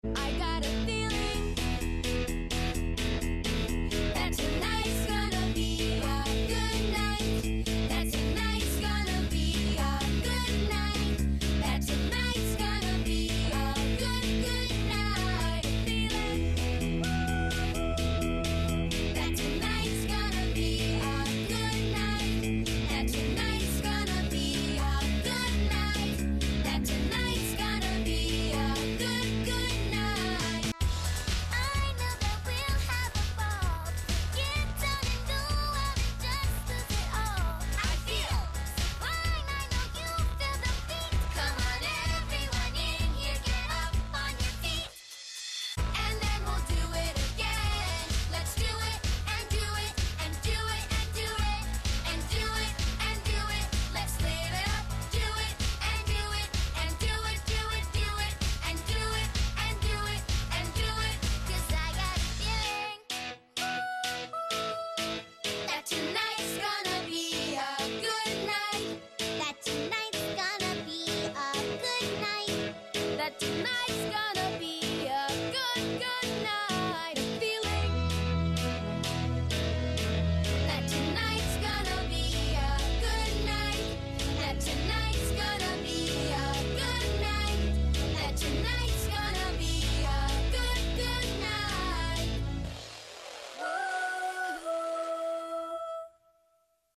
but it’s only the singing parts